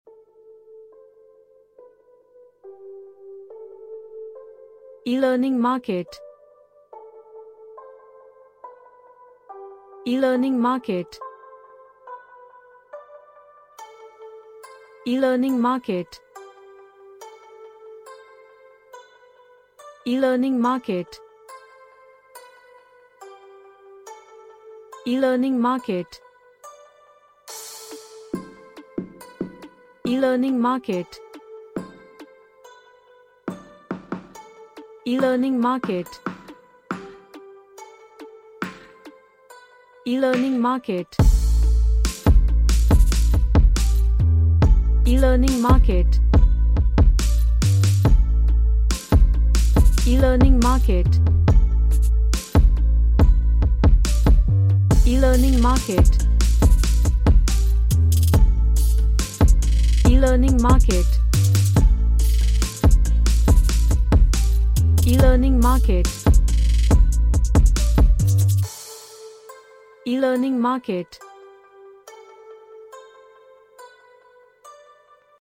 A semi korean pop track
Sci-Fi / Future